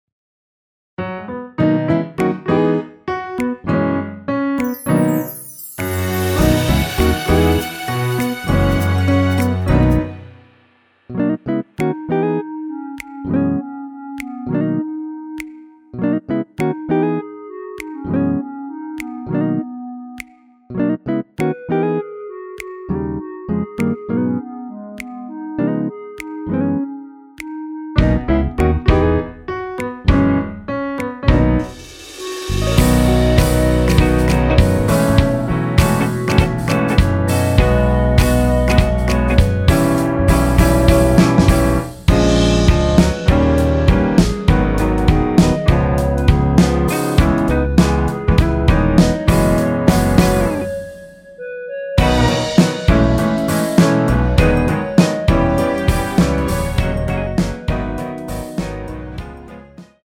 원키에서 (-4)내린 멜로디 포함된 MR 입니다.(미리듣기 참조)
앞부분30초, 뒷부분30초씩 편집해서 올려 드리고 있습니다.
중간에 음이 끈어지고 다시 나오는 이유는